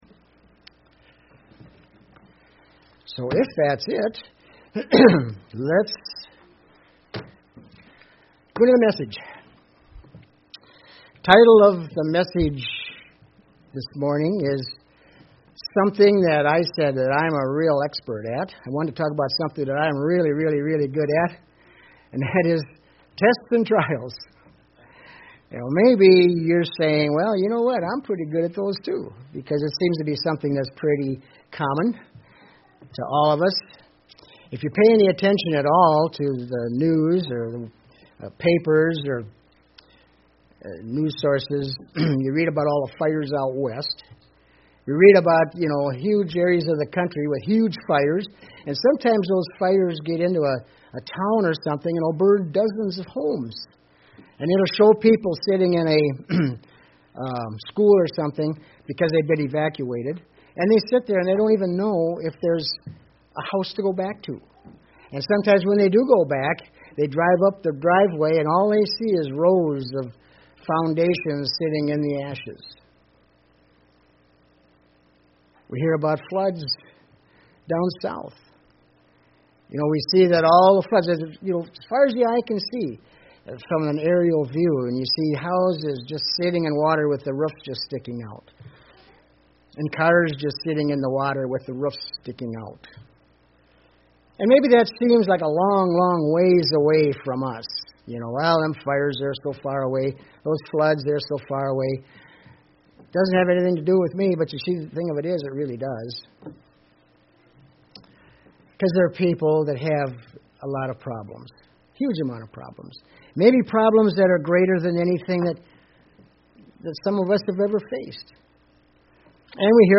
Sermons
Given in Southern Minnesota